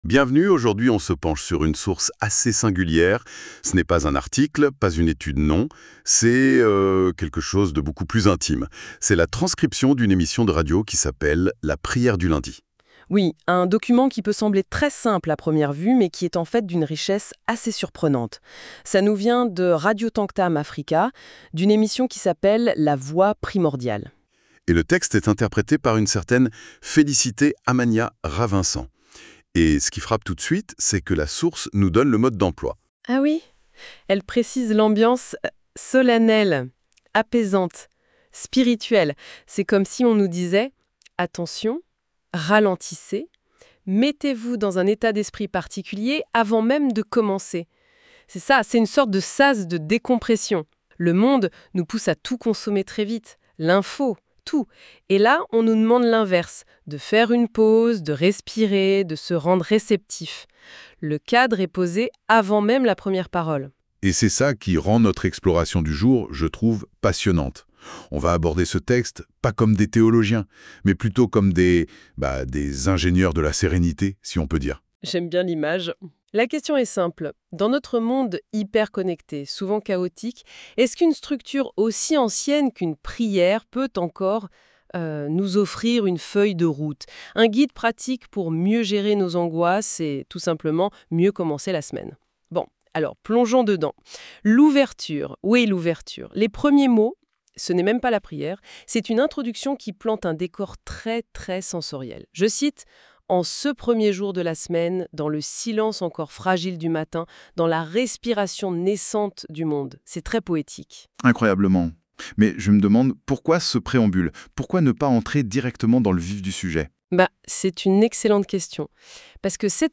Radio TAMTAM AFRICA LA PRIÈRE DU LUNDI – 09 FÉVRIER 2026 Foi – Espérance – Force intérieure Podcast : La Voix Primordiale 09 février 2026